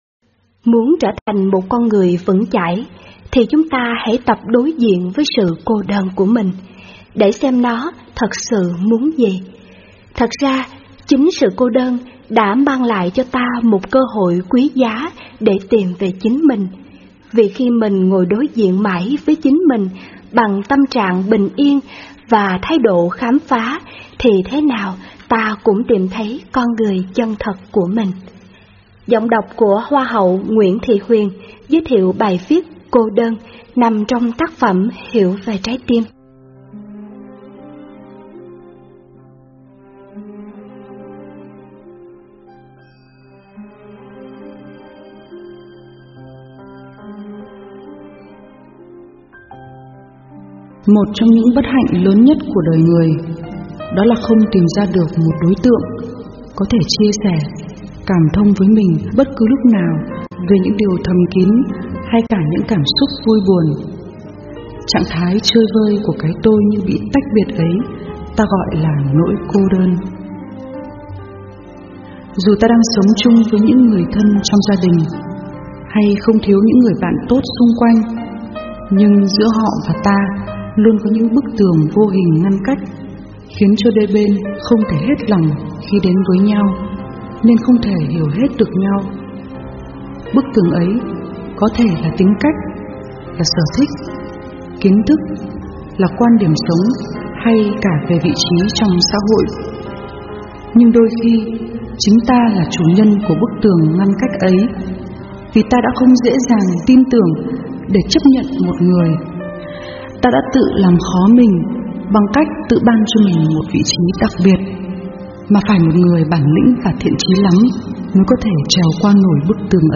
Sách nói mp3